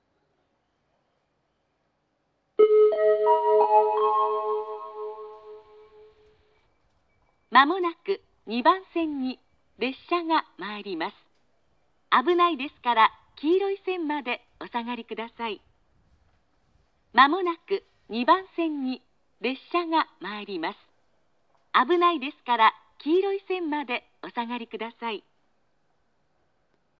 接近放送
発車メロディー(朝の静けさ)   こちらは女声Verです。